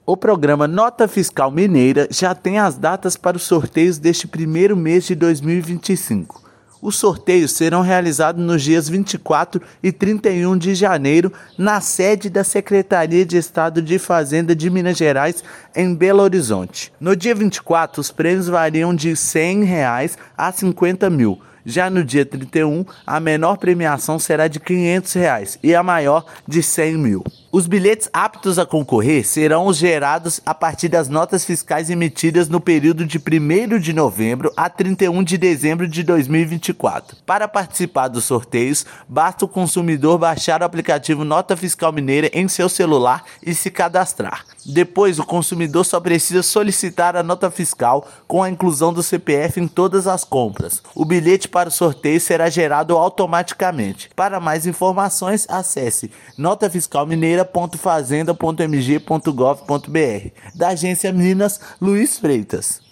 Haverá extrações nos dias 24 e 31/1, com prêmios a partir de R$ 100 e dois destaques, de R$ 50 e R$ 100 mil. Ouça matéria de rádio.